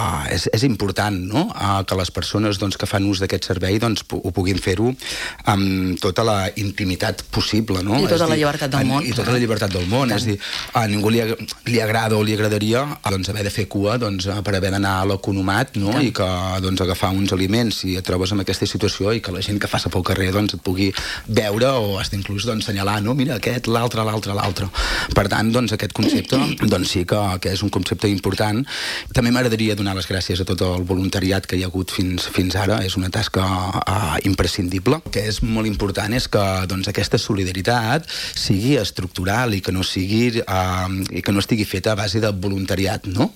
Moment de l'entrevista d'ahir